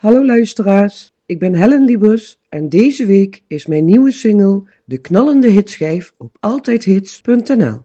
sprankelende single
Deze vrolijke meezinger